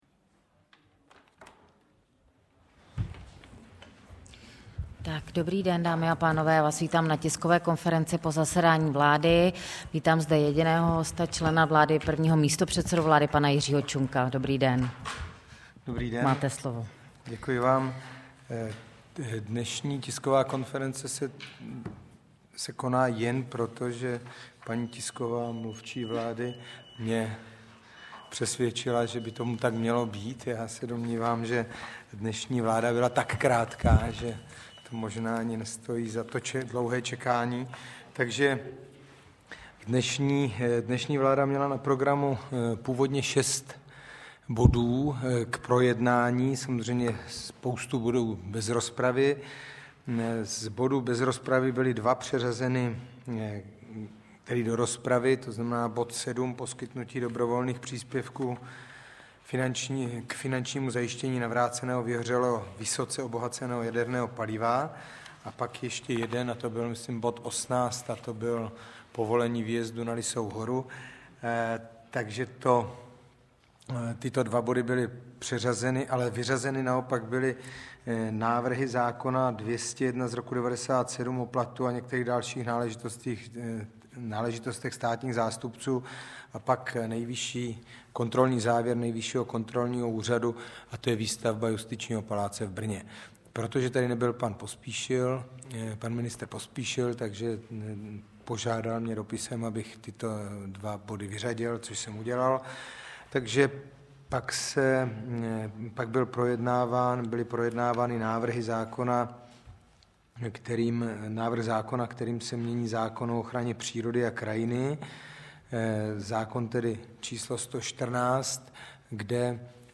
Tisková konference po jednání vlády 17. září 2008